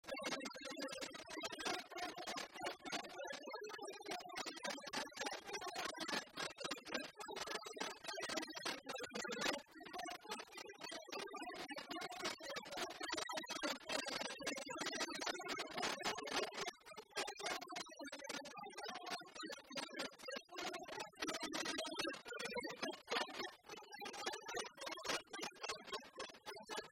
Valse
Château-d'Olonne (Le)
danse : valse
Pièce musicale inédite